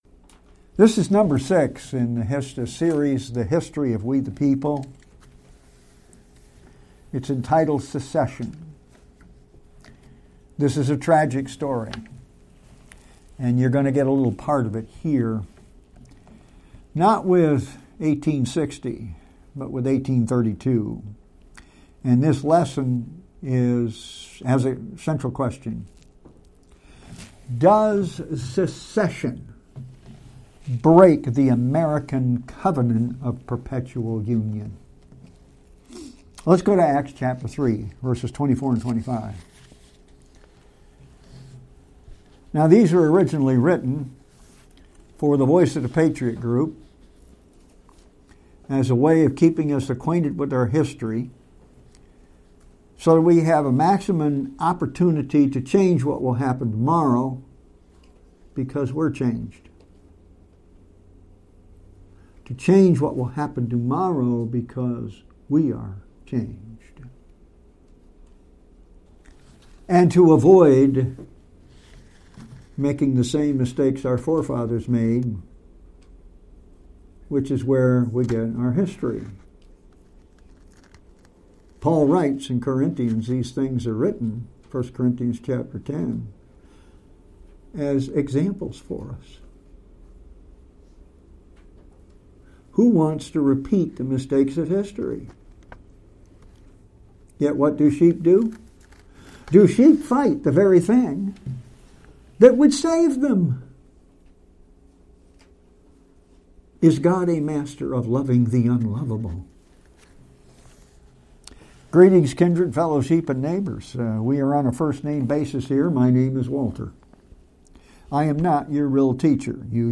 2022 Sermons Visiting Pastor Sermons